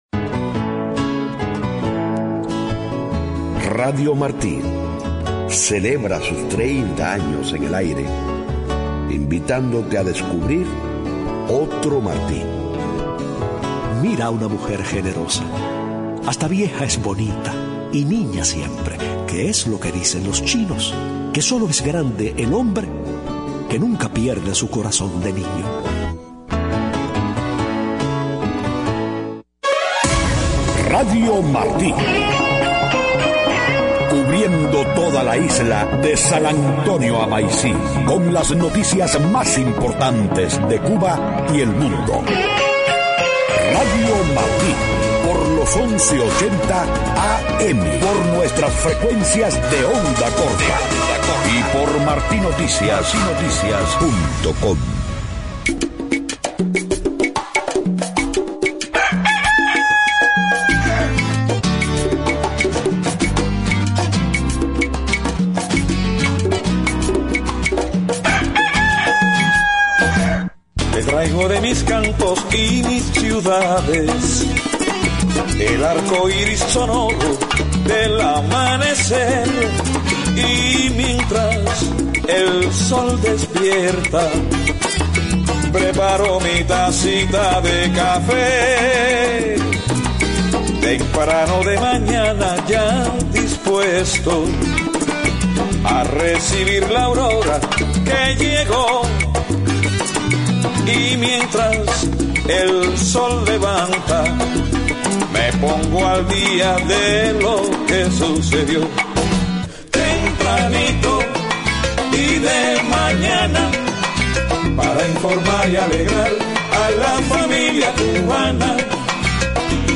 5:00 a.m. Noticias: EEUU anuncia nueva ronda de conversaciones con Cuba para este 27 de febrero en Washington. Comenzó en Washington la cumbre contra el extremismo violento organizada por la Casa Blanca.